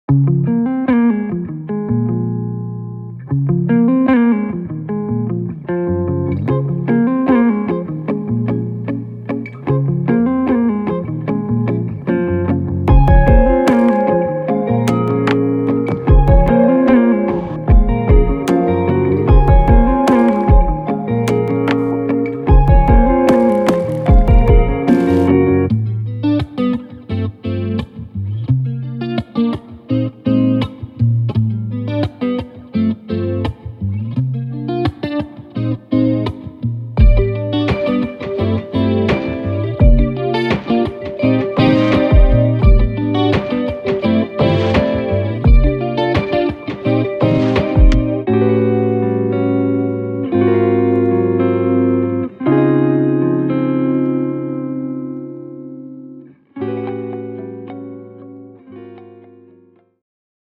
Rnb
NYC guitarist